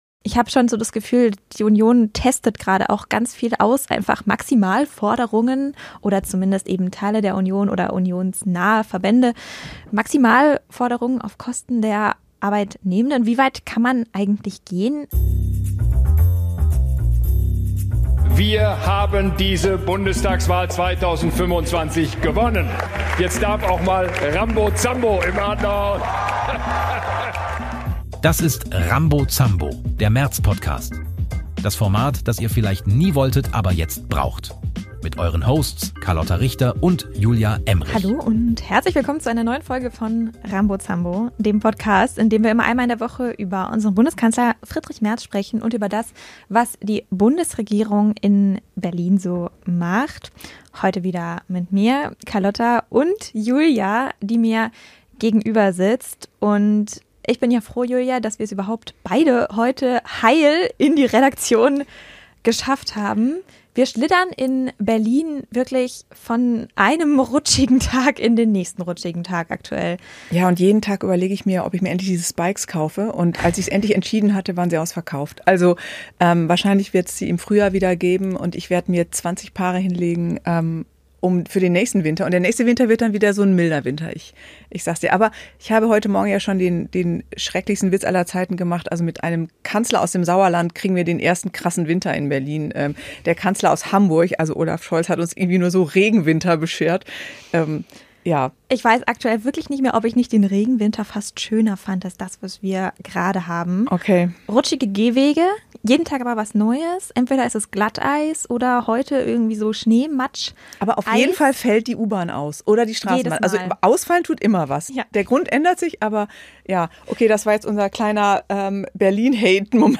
Erweiterte Suche Rettet Merz die SPD? vor 2 Monaten Rambo Zambo - Der Merz-Podcast 32 Minuten 26.3 MB Podcast Podcaster Rambo Zambo – Der Merz-Podcast Zwei Frauen.